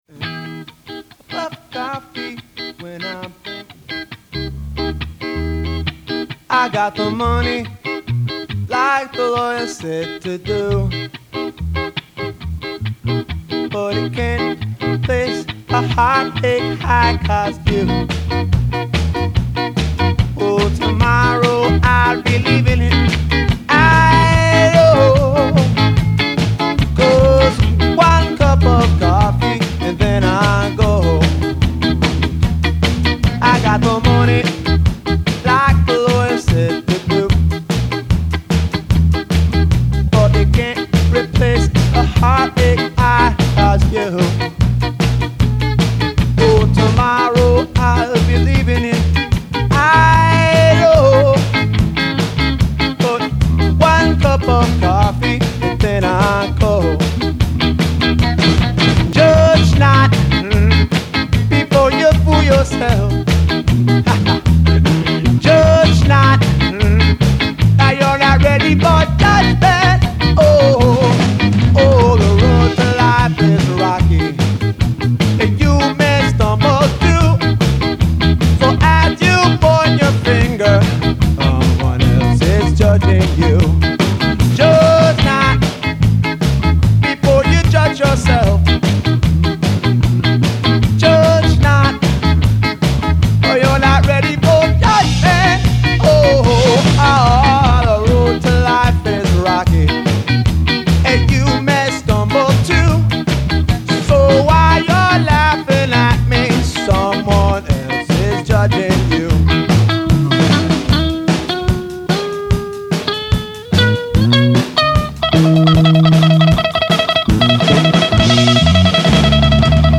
(Studio)